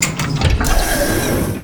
DoorOpen5.wav